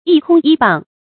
一空依傍 注音： ㄧ ㄎㄨㄙ ㄧ ㄅㄤˋ 讀音讀法： 意思解釋： 指在藝術、學術等方面獨創而全不摹仿。